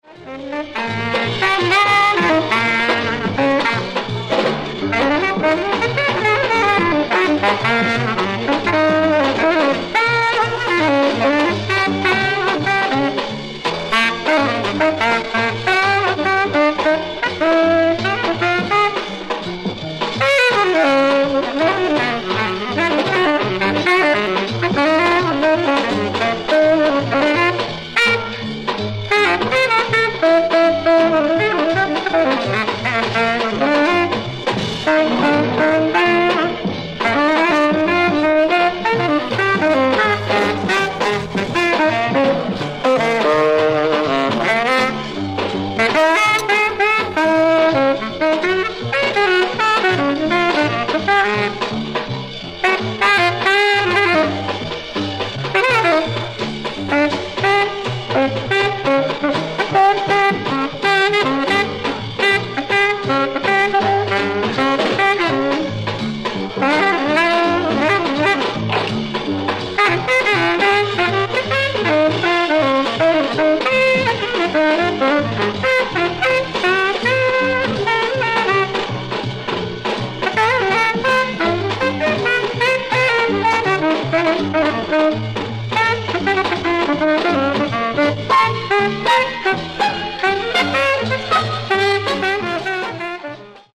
ライブ・アット・シュトゥットガルト、ドイツ 01/29/1963
※試聴用に実際より音質を落としています。